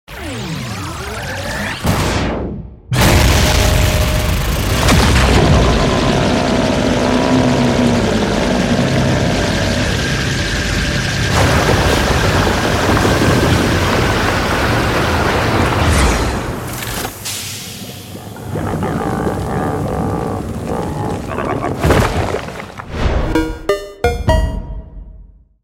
🔥 À la demande de la communauté, nous avons repensé le son emblématique du laser de Reg pour lui donner plus de puissance, de profondeur… et une touche Noisy Core !